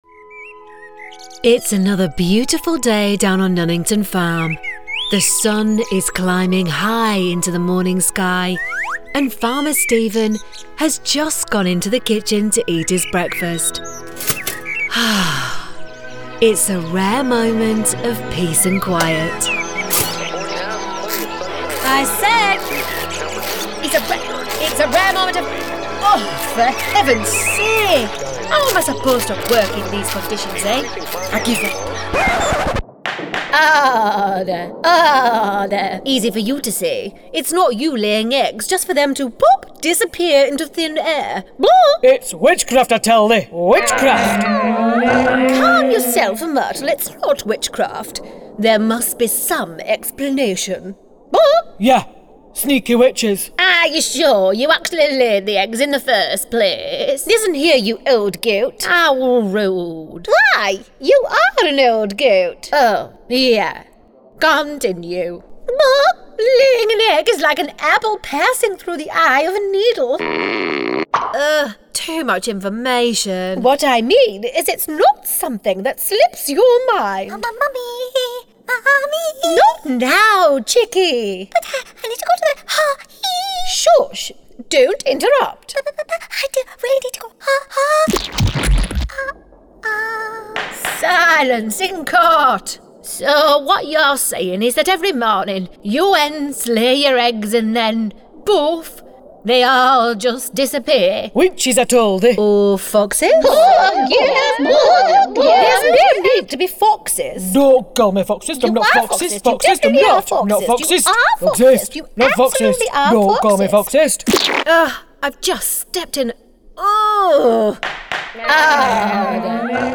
Female / 30s, 40s / English / Gaming, Northern
Showreel